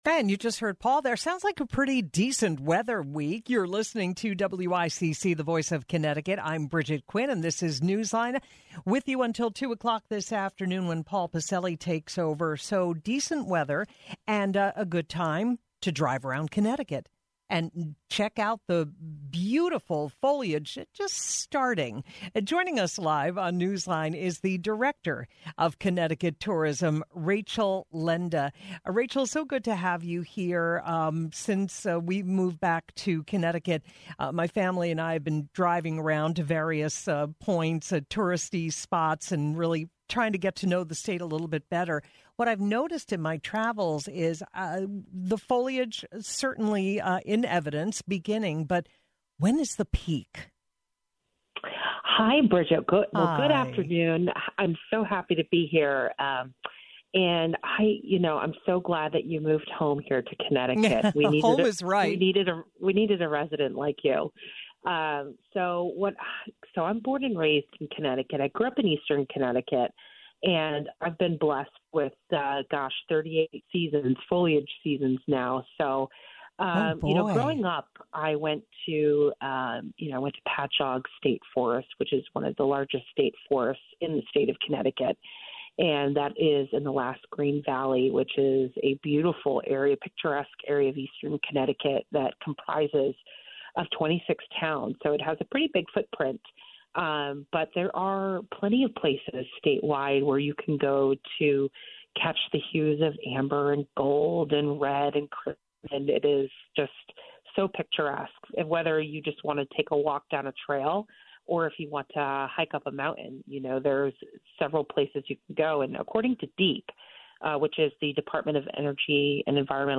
checked in live from Tel Aviv